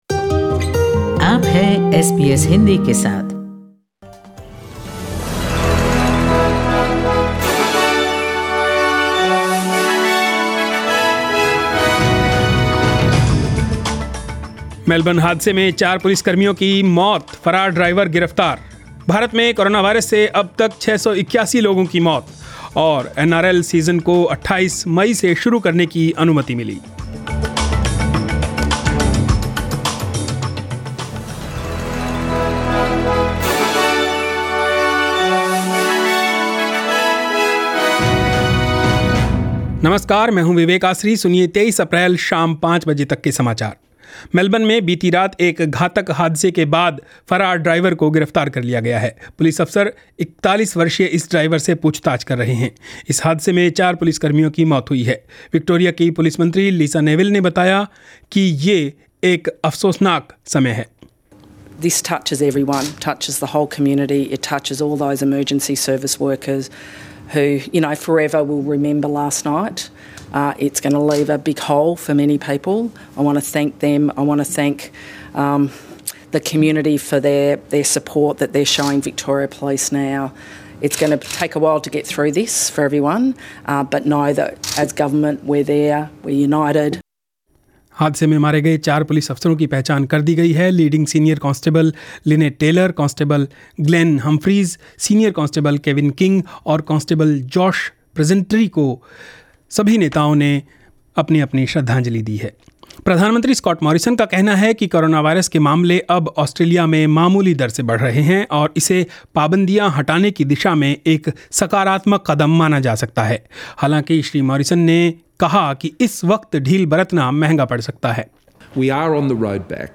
In this bulletin: *Victoria police names of the four members killed in Melbourne collision last night. *International medical student visas eased, and NRL to reboot the stalled competition on May 28.